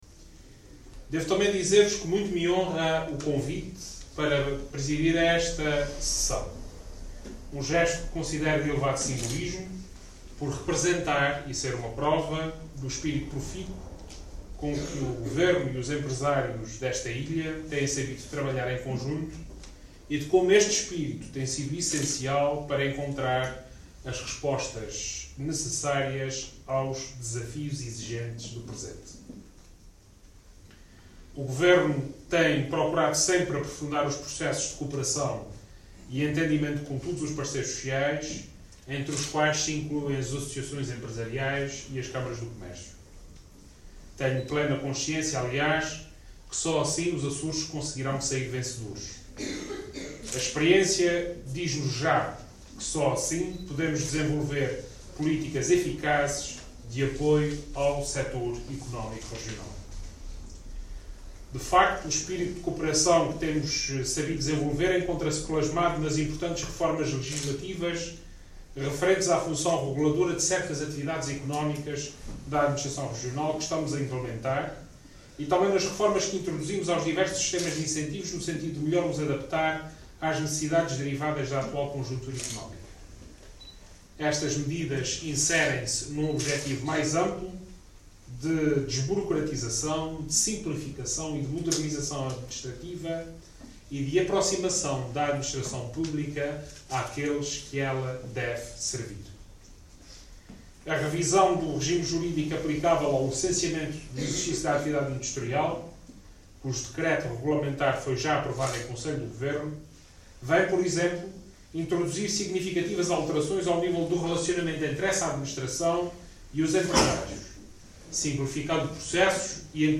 Segundo Vasco Cordeiro, que falava na cerimónia de inauguração da sede da Associação Comercial e Industrial da Ilha do Pico, na madalena, "de pouco serve aos Açores estarem dotados de sistemas de incentivos que constituem um exemplo nacional assim como de bons programas de apoio para as mais diferentes áreas se, depois, eles não servirem o fim a que se destinam, que é apoiar os empresários e os trabalhadores açorianos a ajudar a que a Região possa gerar cada vez mais riqueza e oportunidades".